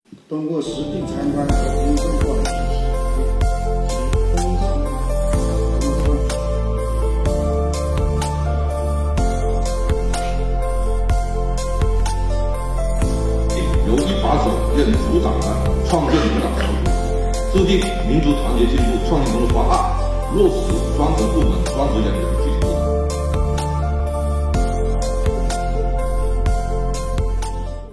防城港市税务局在全市民族工作会议暨全国民族团结进步示范市工作推进会上作经验发言